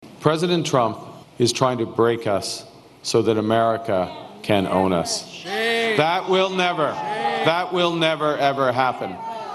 His acceptance speech very early this morning, he addressed the elephant in the room.